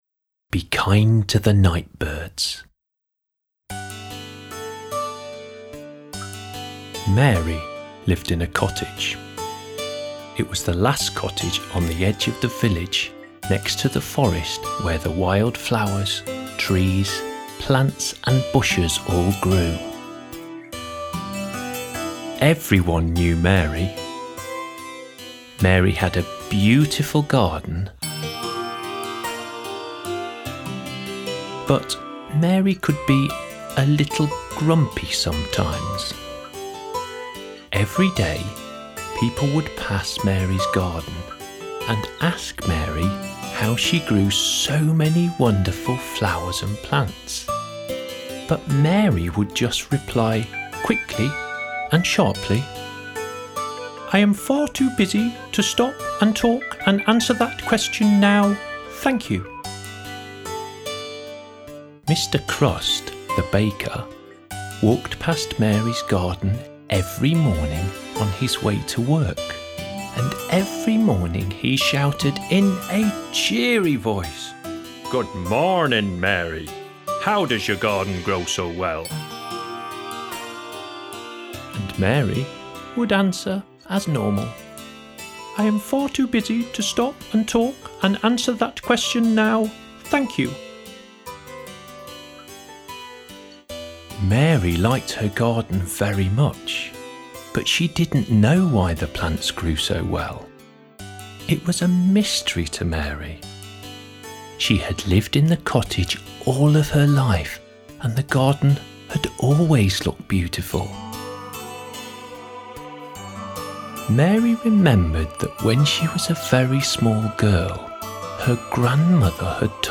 Good Night Stories